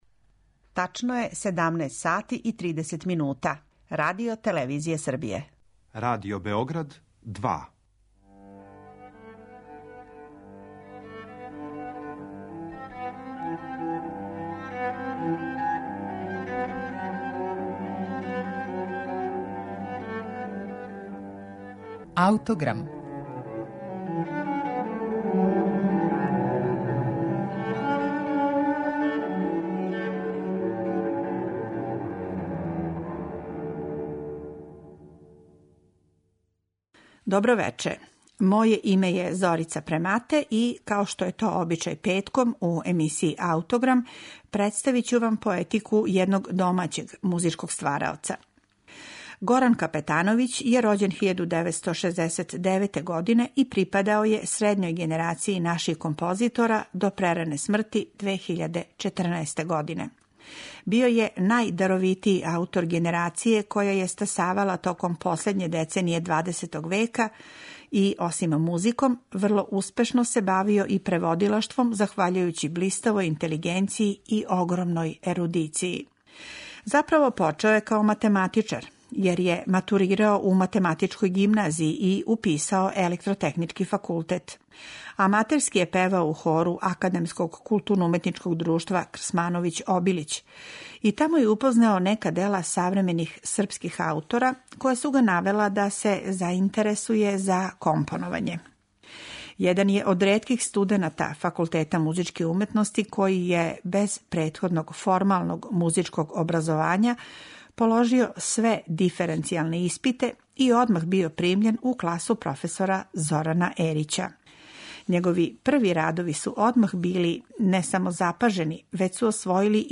за два сопрана, камерни ансамбл и траку